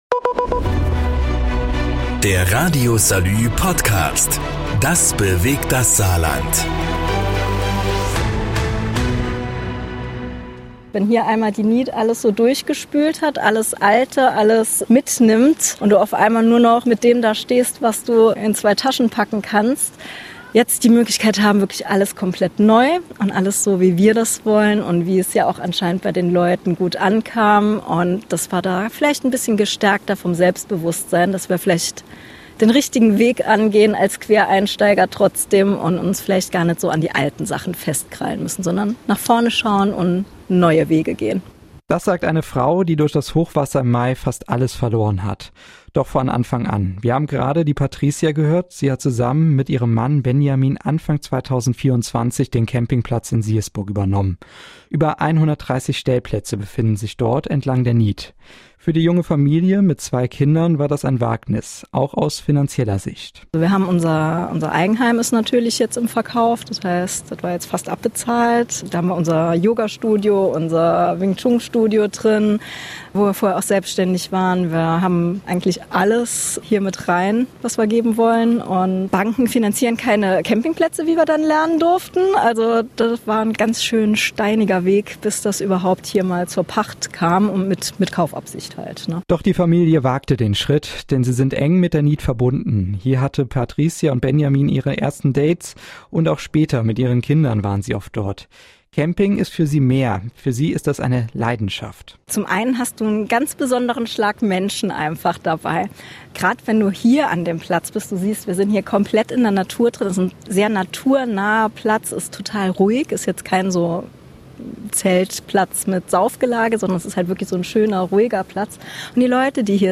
berichtet